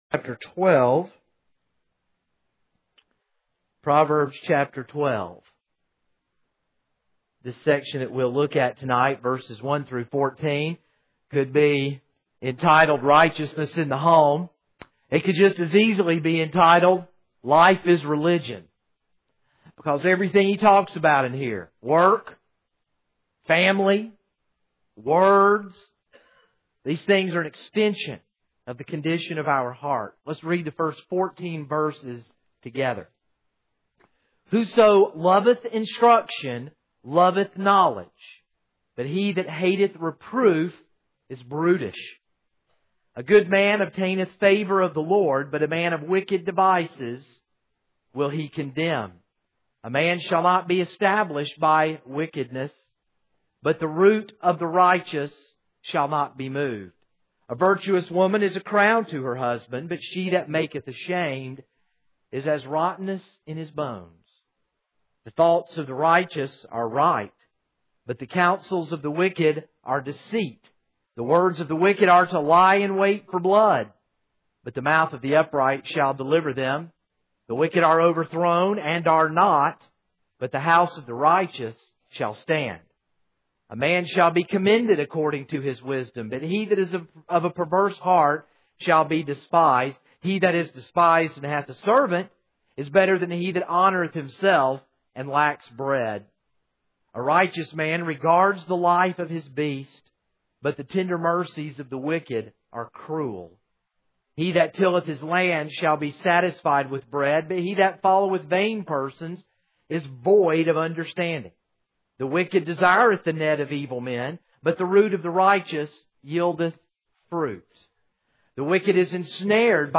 This is a sermon on Proverbs 12:1-14.